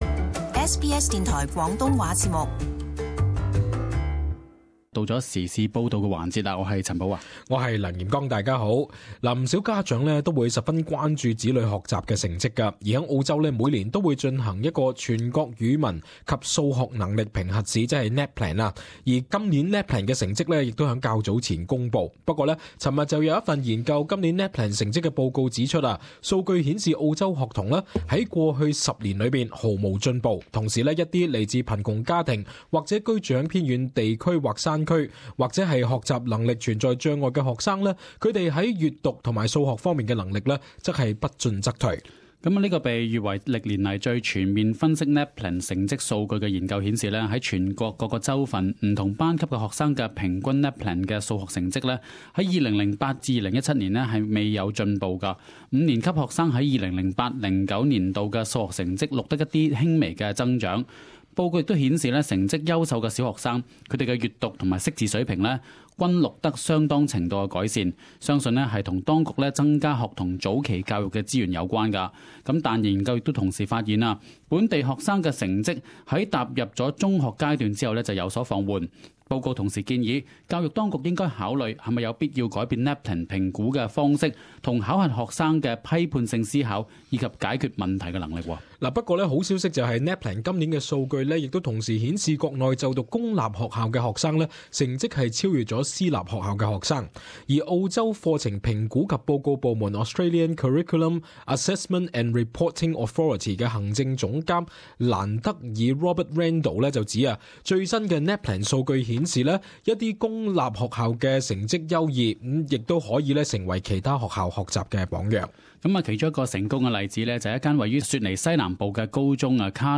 【時事報導】公校今年NAPLAN成績超越私校